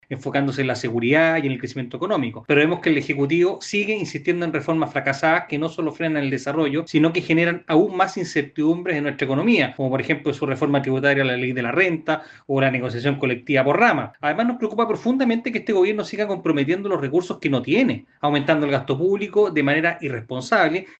El diputado republicano Agustín Romero expresó su preocupación por el aumento del gasto público que significan los compromisos programáticos del Gobierno.